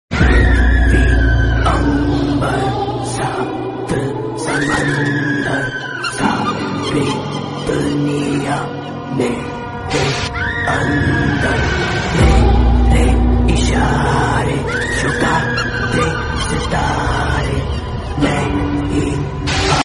Creepy Witch Walking In Jungle Sound Effects Free Download